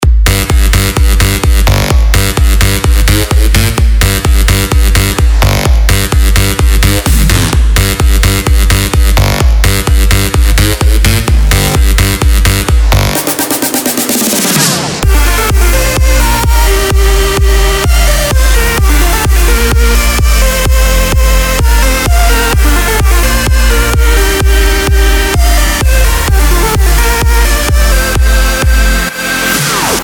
• Качество: 192, Stereo
Новый Трек в стиле Датч-Хаус для любителей клубых рингтонов!